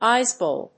/ˈaɪsɔˌbɛl(米国英語), ˈaɪsɔ:ˌbel(英国英語)/